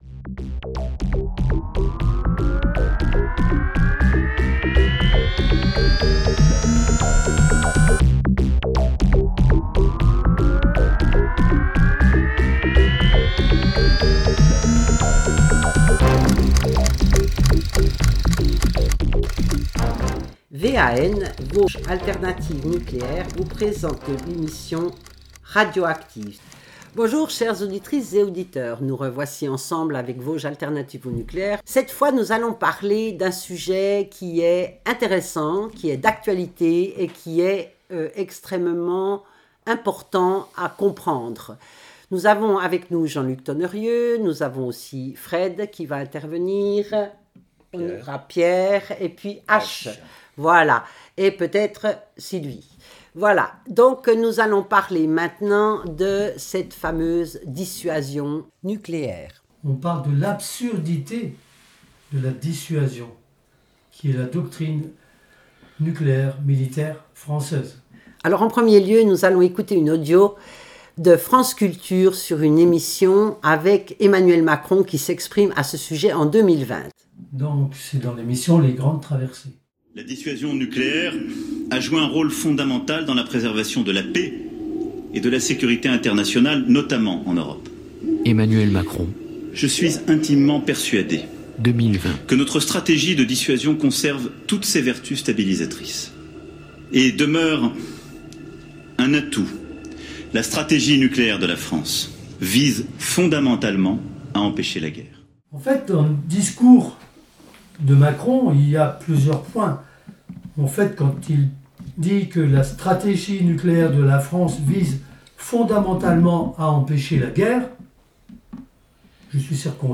Cette semaine dans In Onde de vie, VAN (vosges alternative nucléaire) vous propose une discussion sur la dissuasion nucléaire